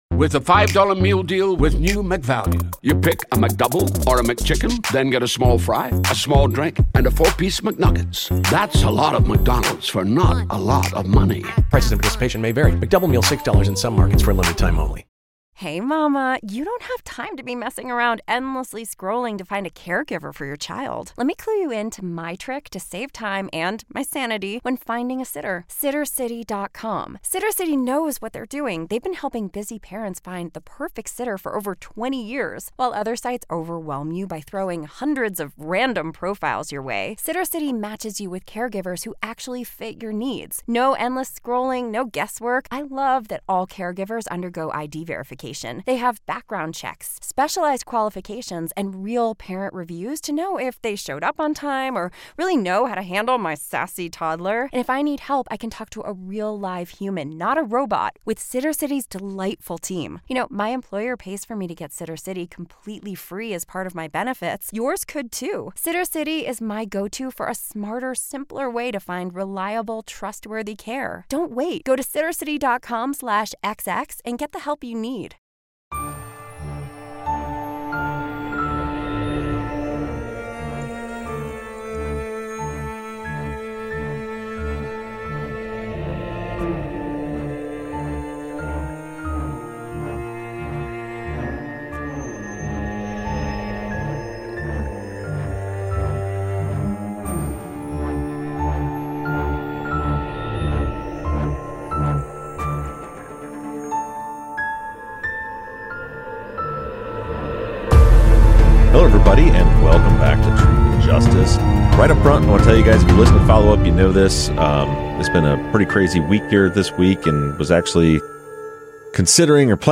streams with Patrons for an AMA session. Questions range from info on cases to Birthday plans to overtipping and more.